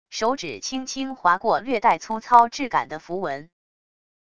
手指轻轻滑过略带粗糙质感的符文wav音频